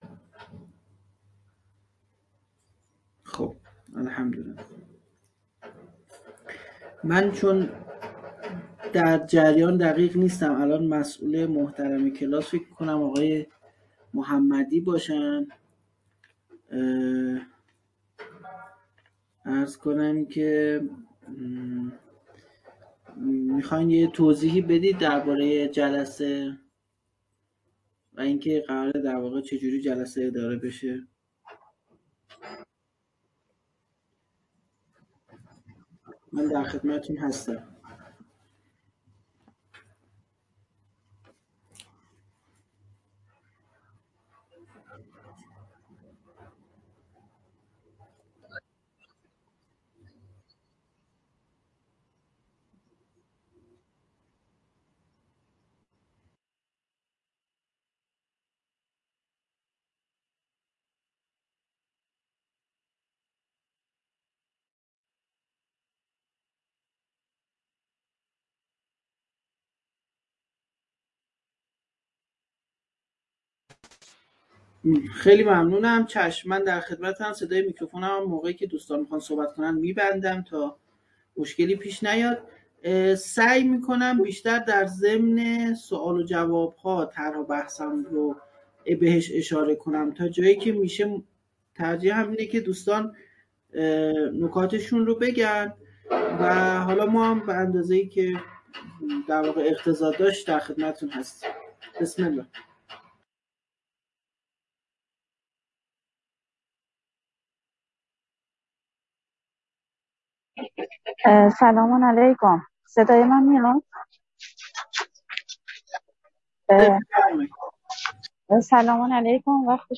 آزادی انسان - جلسه-پرسش-و-پاسخ_آزادی-انسان